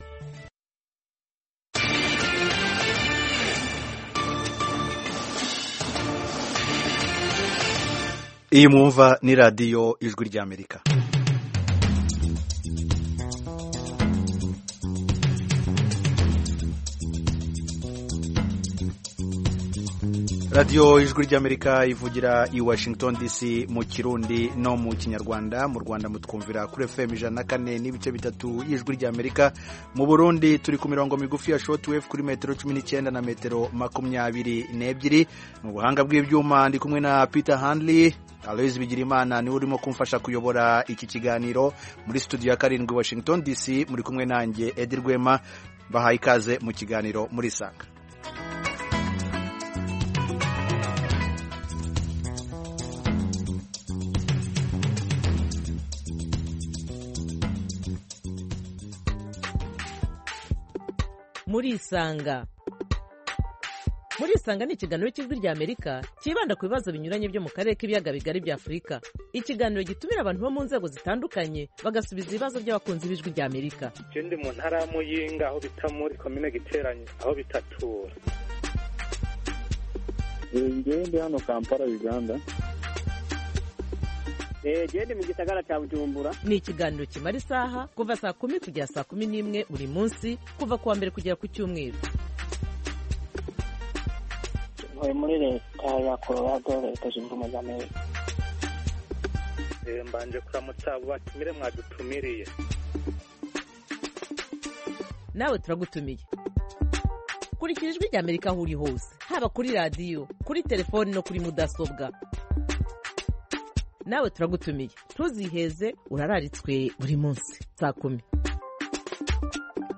Murisanga (1400-1500 UTC): Murisanga itumira umutumirwa, cyangwa abatumirwa kugirango baganire n'abakunzi ba Radiyo Ijwi ry'Amerika. Aha duha ijambo abantu bifuza kuganira n'abatumirwa bacu, batanga ibisobanuro ku bibazo binyuranye bireba ubuzima bw'abantu.